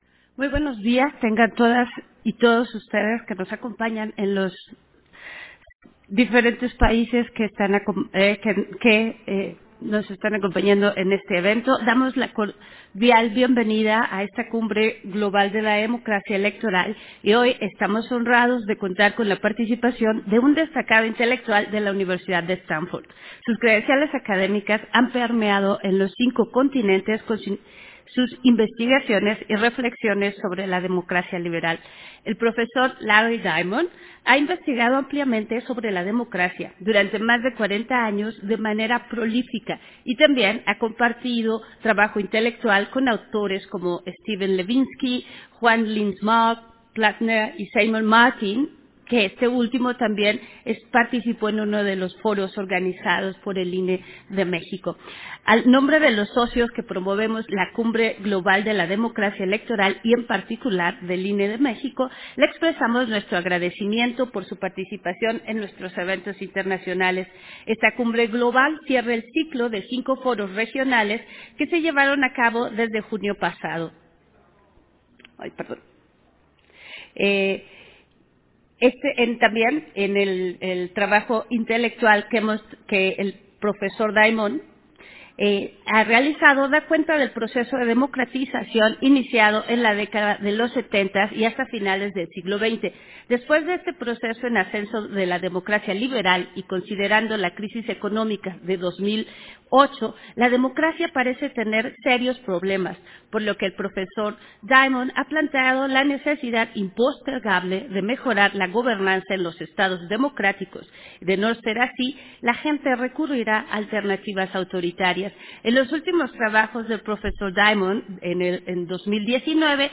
Conferencia magistral: Principios y valores democráticos en la era post-pandemia, dictada por Larry Diamond, en la Cumbre Global de la Democracia Electoral